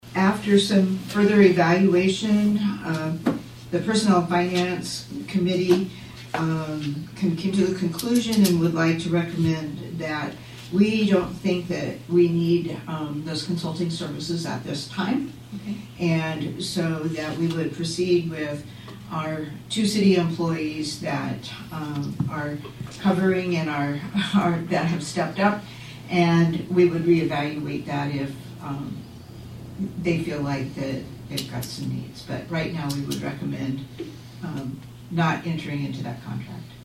City Council Member Elaine Otte invoked rule number two to discuss this item.